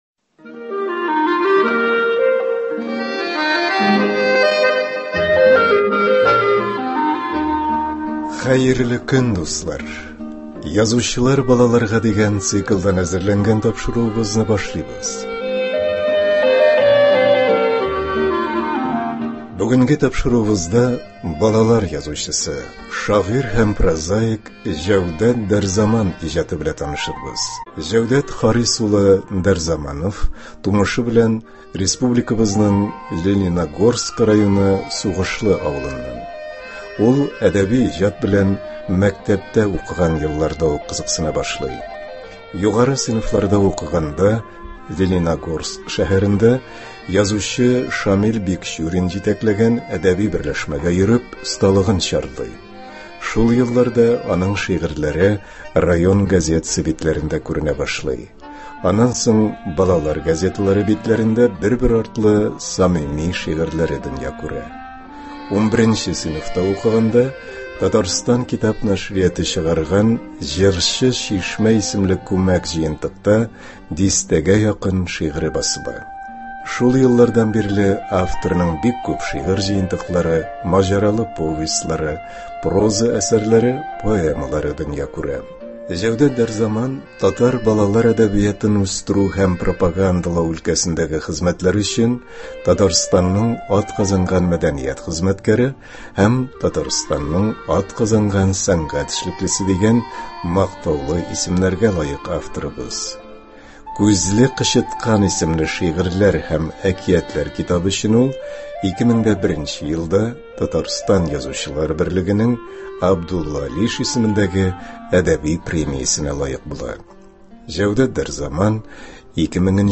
Бүгенге тапшыруыбызда балалар язучысы, шагыйрь һәм прозаик Җәүдәт Дәрзаман иҗаты белән танышырбыз. Авторның редакциябез фондында сакланган үз язмасында берничә шигырен дә ишетербез.